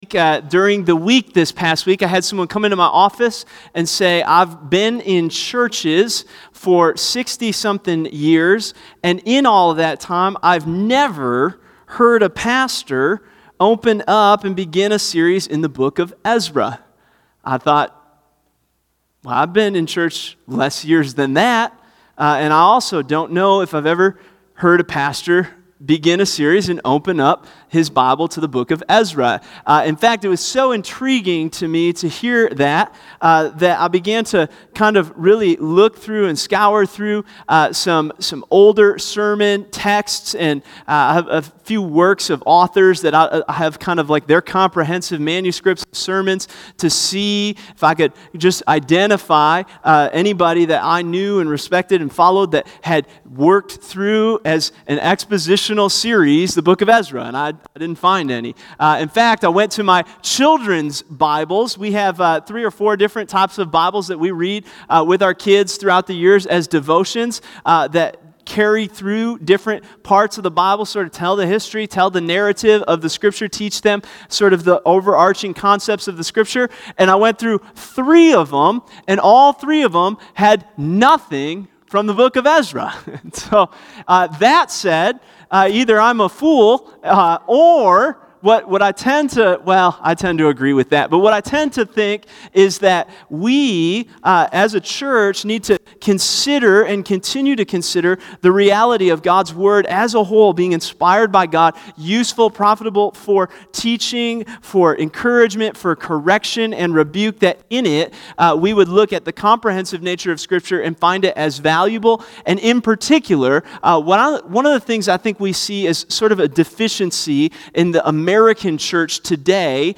Bible Text: Ezra 1:5-3:3 | Preacher